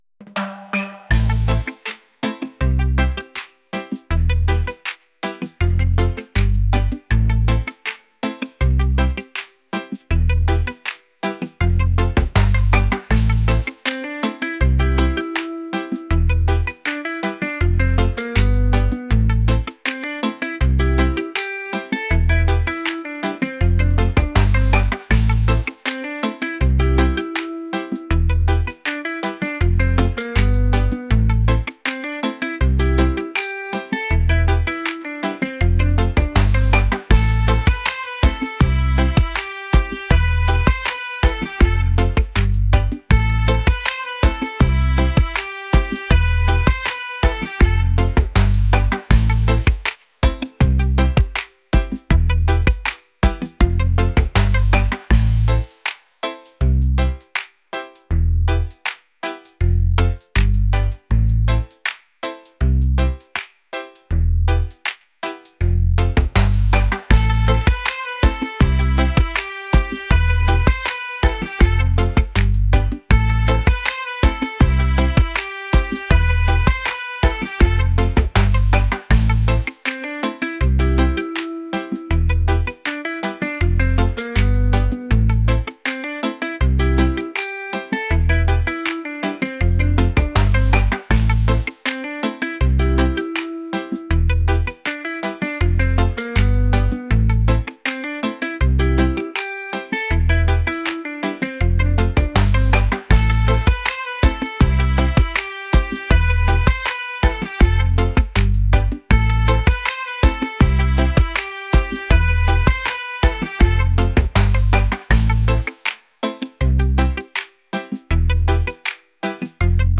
reggae | lofi & chill beats | world